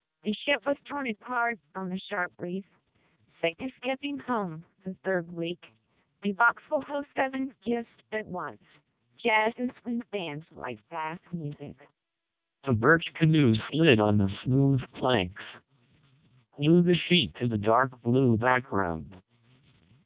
TWELP 600 bps Robust vocoder and MELPe 600 bps vocoder were tested, using ITU-T P.50 speech base for 20 different languages.
You can play and listen short samples of the source speech as well as the speech processed by both vocoders for any of 20 languages, using links in the table below.
LanguageSource speechMELPe 600 bpsTWELP 600 bps Robust